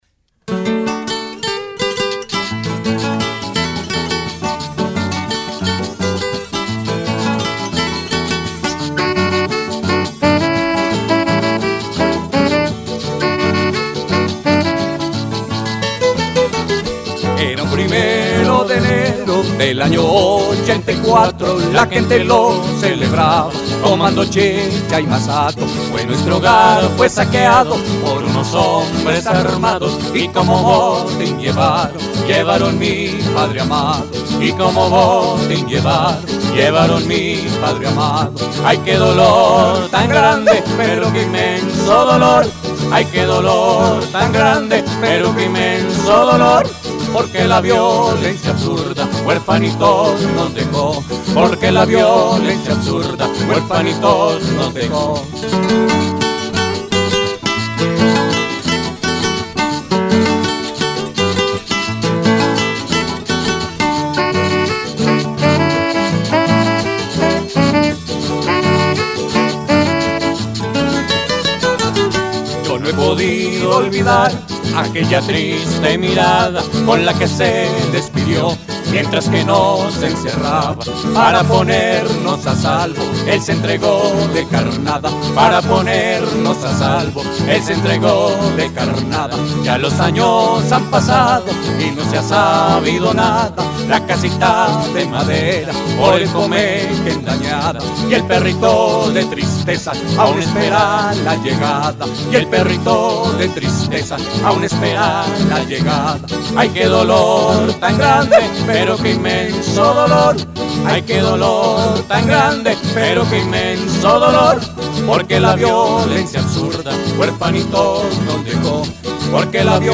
Canción
voz y guitarra.
requinto y tiple.
saxofón y voz.